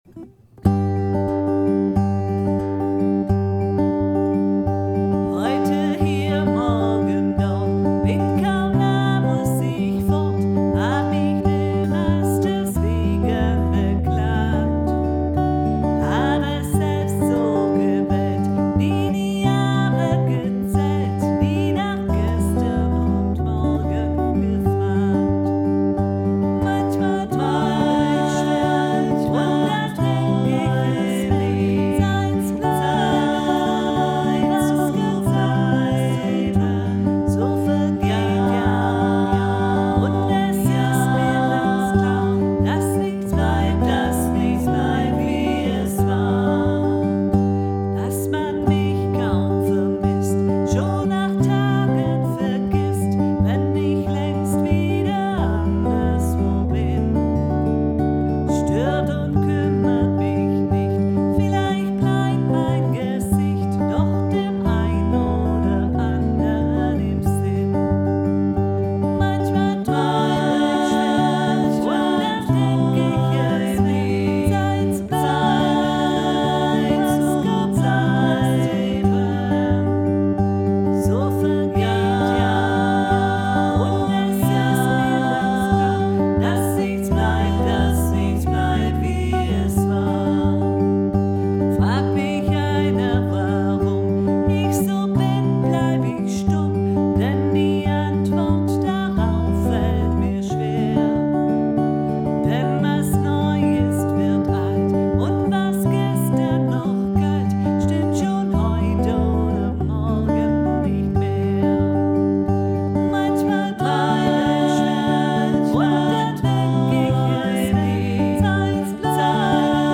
Offenes Singen Heute hier morgen dort 2